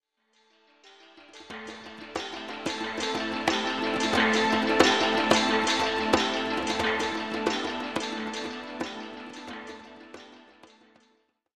Music Transition; Shaker Ethnic Drums Fade In And Out.